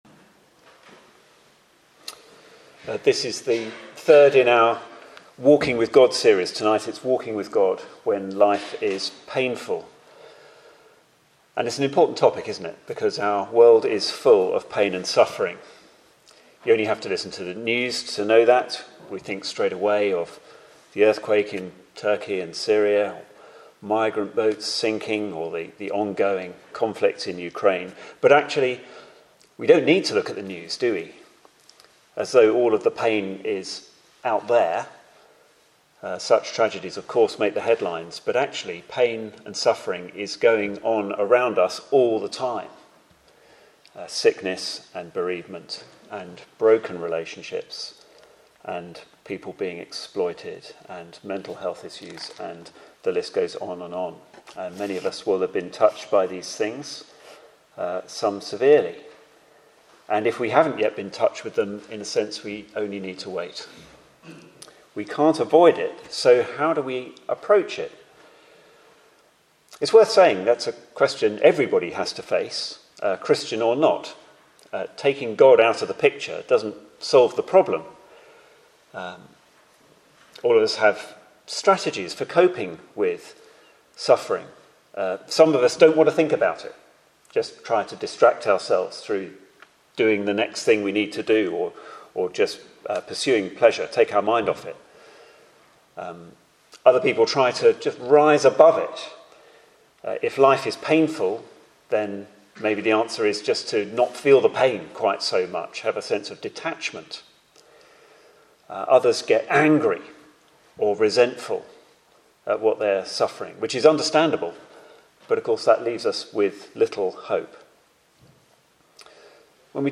Media for Sunday Evening on Sun 12th Mar 2023 18:00 Speaker